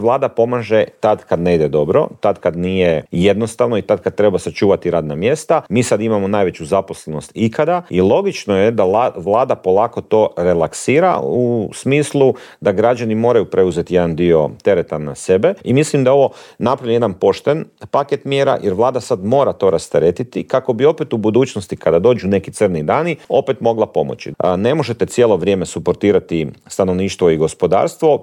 ZAGREB - "Bilo je i vrijeme da se gospodarske mjere relaksiraju, građani sada moraju preuzeti dio tereta na sebe. To neće ubrzati rast inflacije, a važno je da pomognemo onima koji su najugroženiji", u Intervjuu tjedna Media servisa poručio je saborski zastupnik iz redova HSLS-a Dario Hrebak.